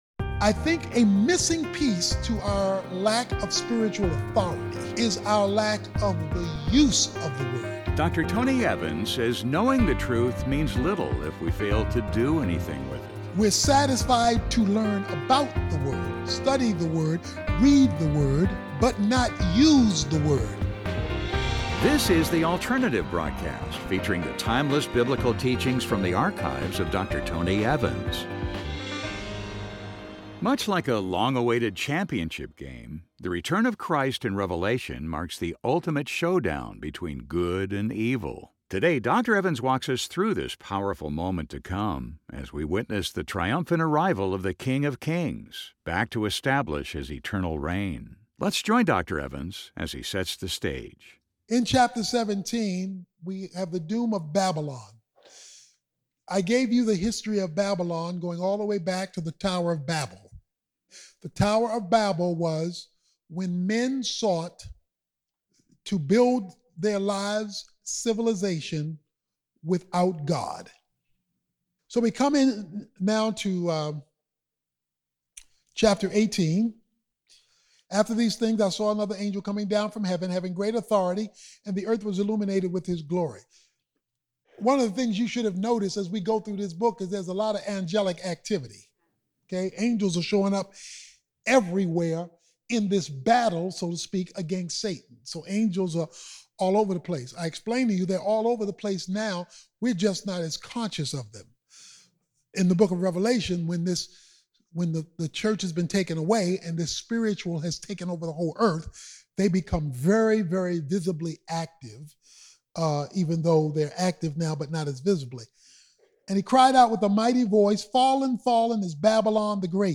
Much like a long-awaited championship game, the return of Christ in Revelation marks the ultimate showdown between good and evil. In this message, Dr. Tony Evans walks us through this powerful moment in time as we witness the triumphant arrival of the King of kings, back to establish His eternal reign.